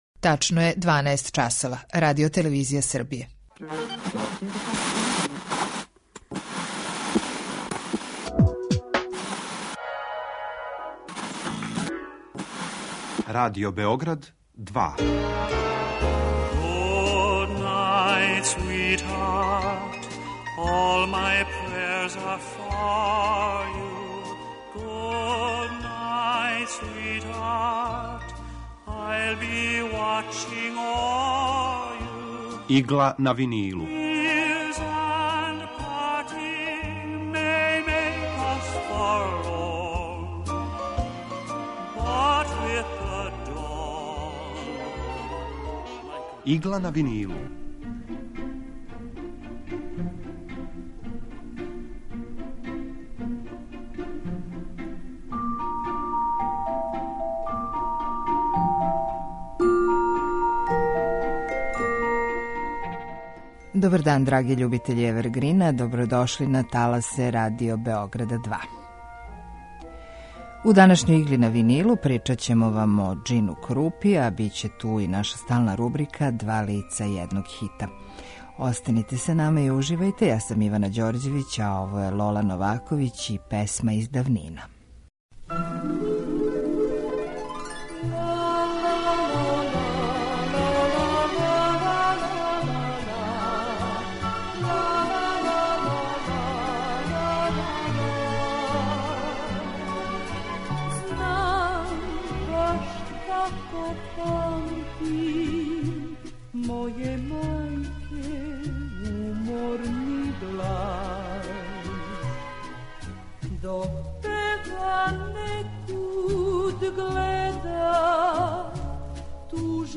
Евергрин музика
У Игли на винилу представљамо одабране композиције евергрин музике од краја 40-их до краја 70-их година 20. века.